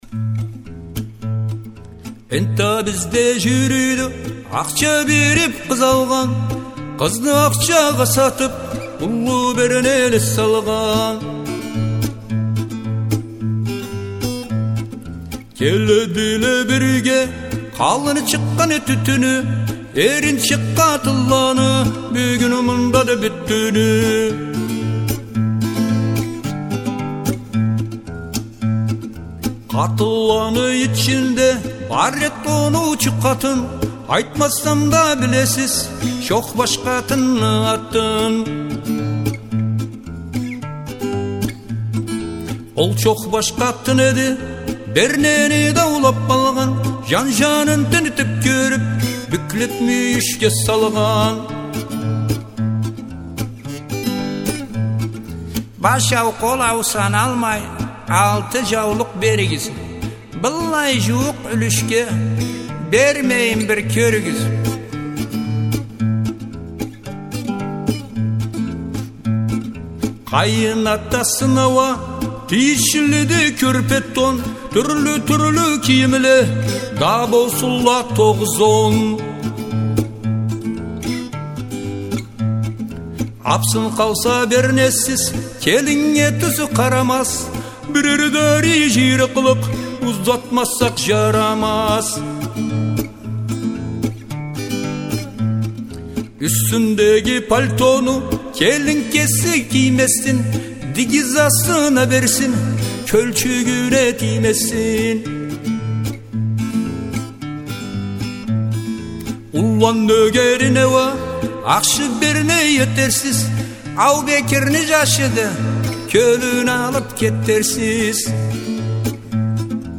чам джыр